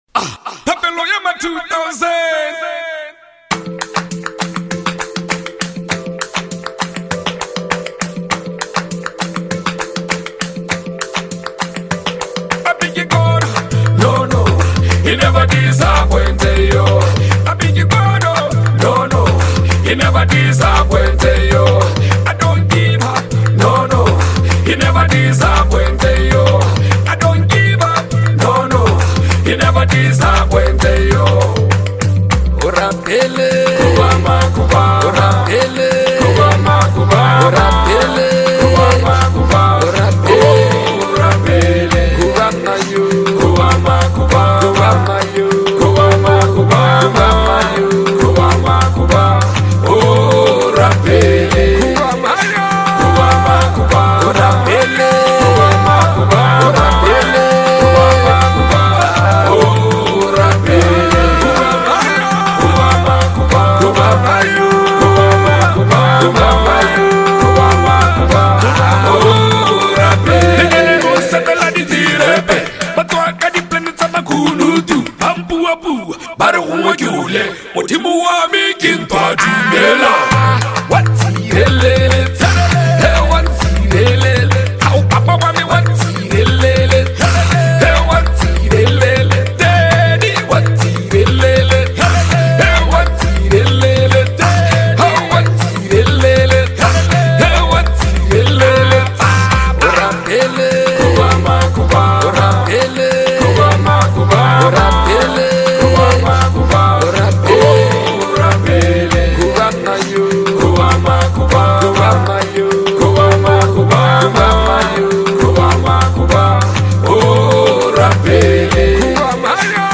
a track that carries both urban and gospel vibes
” a banging record that is already doing magic on charts.